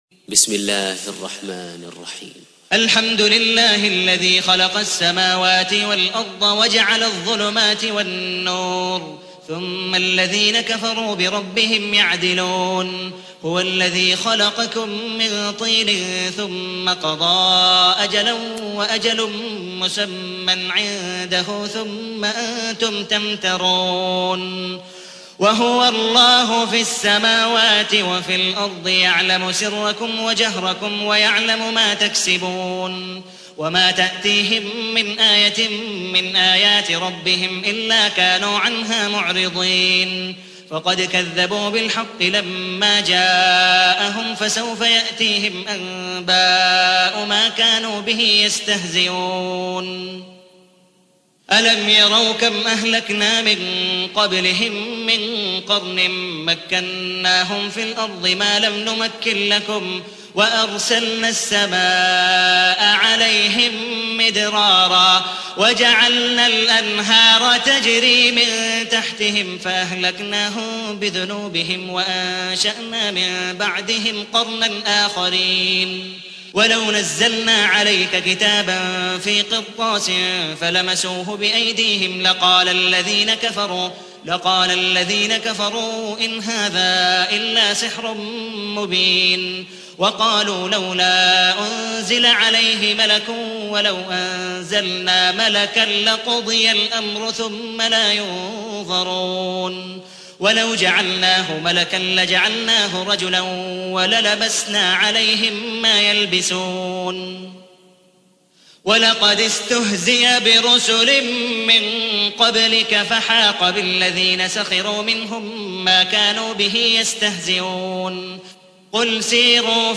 تحميل : 6. سورة الأنعام / القارئ عبد الودود مقبول حنيف / القرآن الكريم / موقع يا حسين